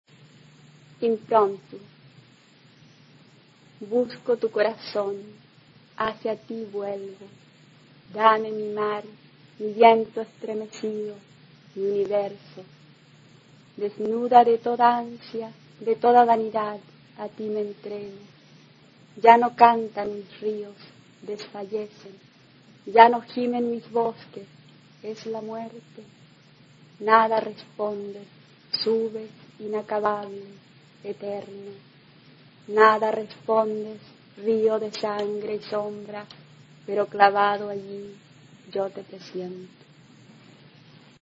Poesía
Poema